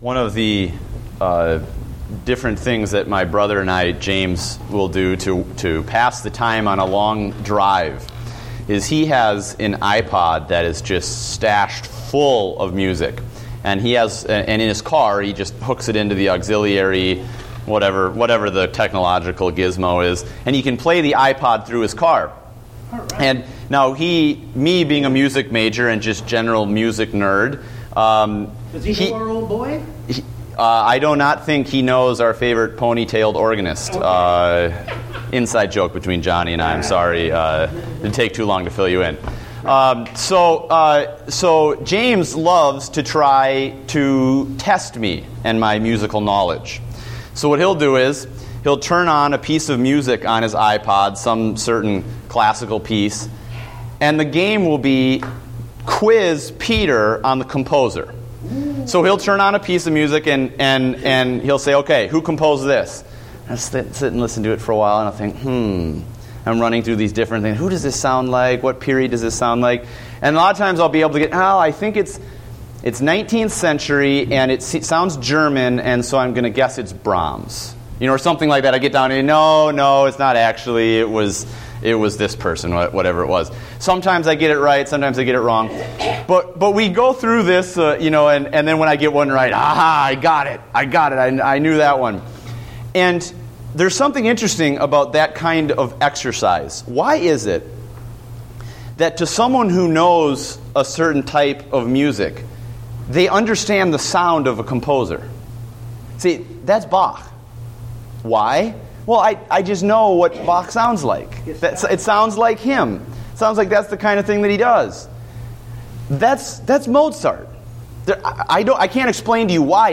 Date: August 3, 2014 (Adult Sunday School)